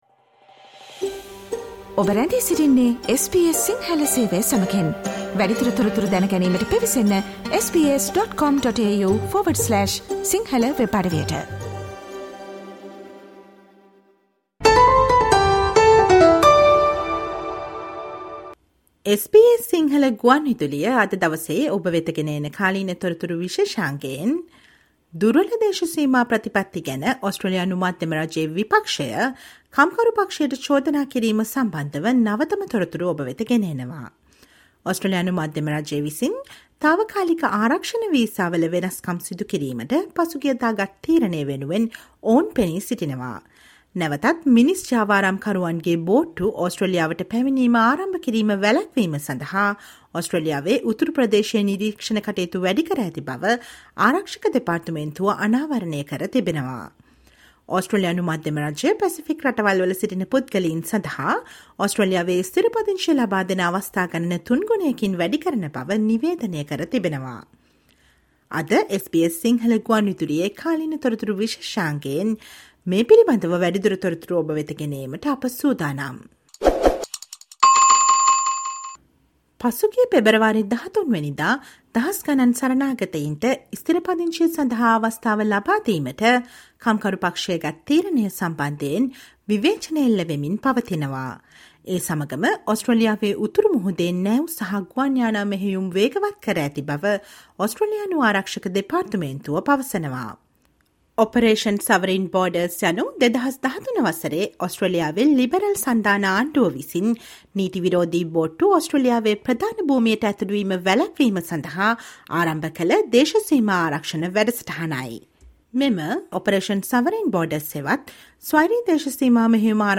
Listen to the SBS Sinhala radio current affair feature on the federal opposition accusation on Labor for weak border policies and tripled number of permanent residency places for people from Pacific countries on 17 Feb